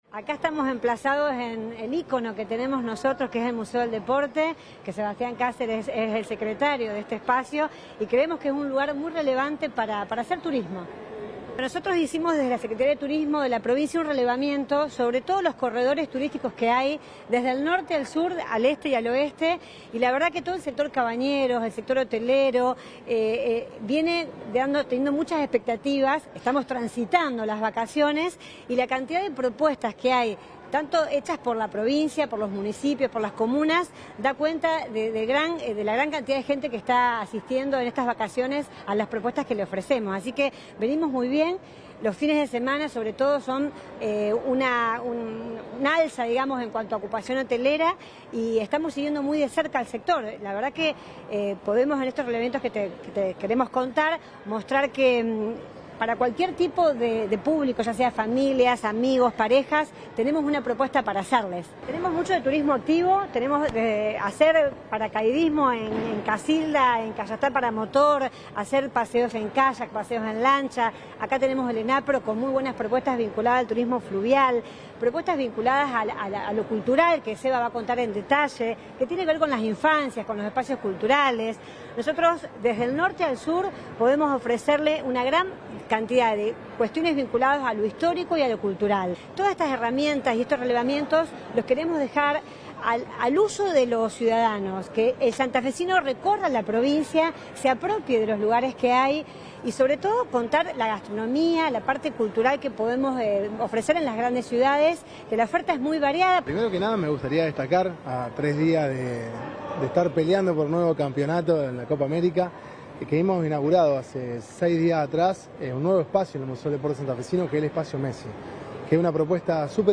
Durante una conferencia, que se realizó en el Espacio Messi del Museo del Deporte de Rosario, la secretaria de Turismo, Marcela Aeberhard, afirmó que “estamos emplazados en el ícono que tenemos en Rosario, que es el Museo del Deporte, un lugar muy relevante para hacer turismo”.